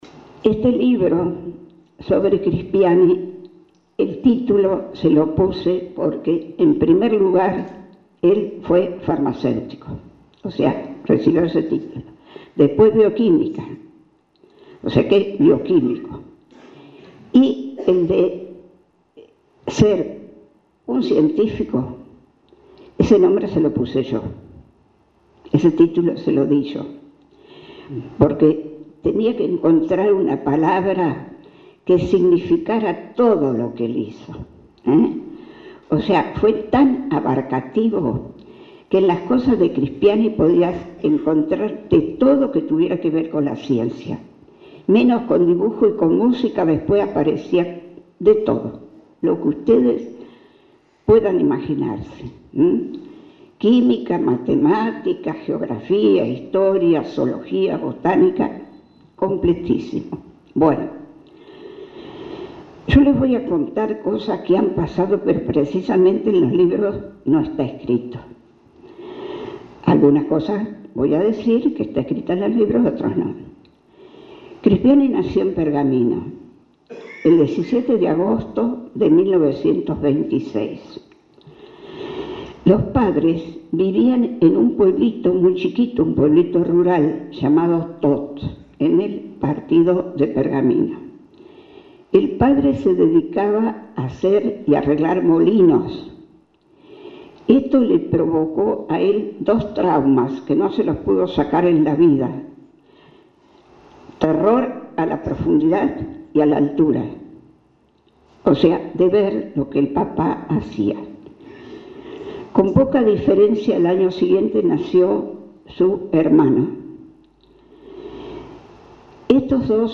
Fue en el salón principal de la Secretaría de Cultura ante un numeroso público.